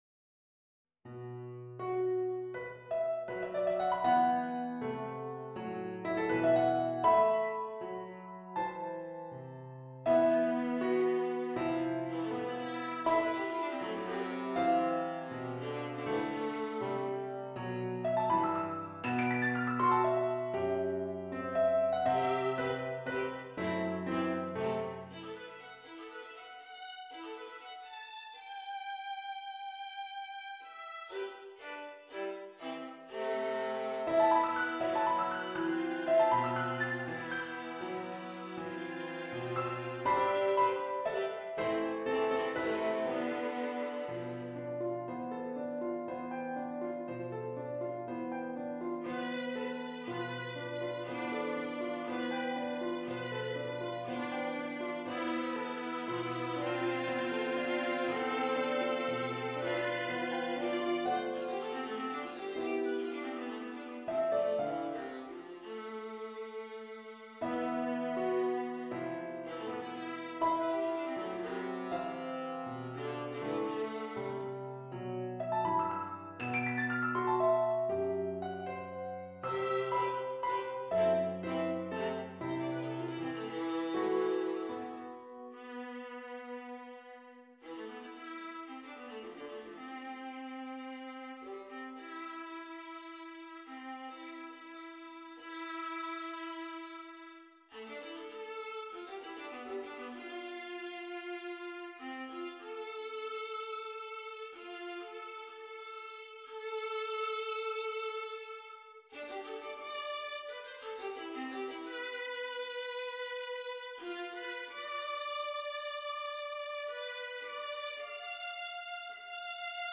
Viola Piano